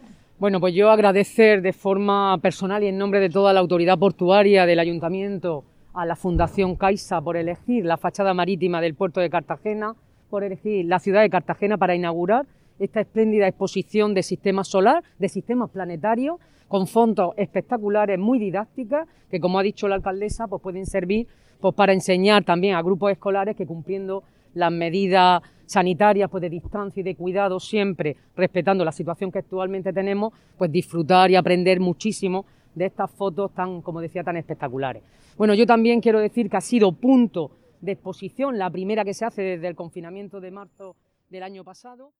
Audio: Declaraciones de la alcaldesa Ana Bel�n Castej�n sobre la exposici�n 'Otros mundos' (MP3 - 2,07 MB)